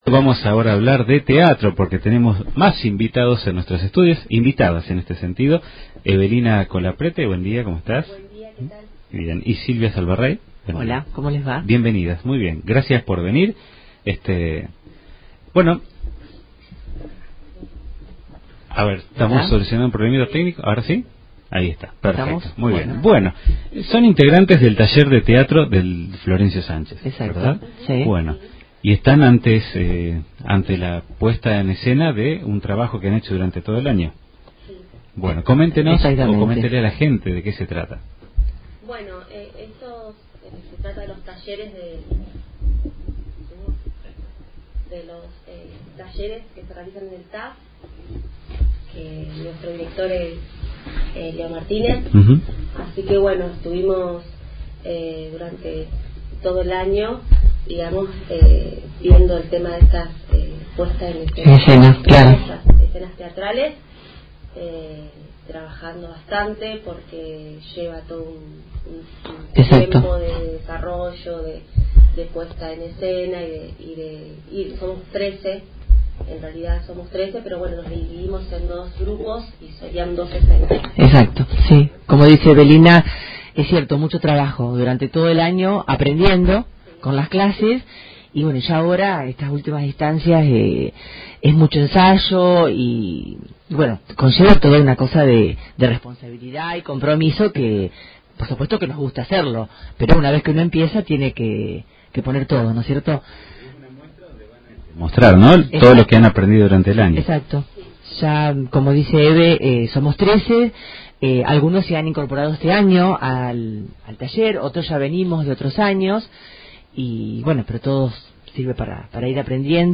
Dos de sus integrantes estuvieron en Radio Rojas para comentar detalles de la puesta.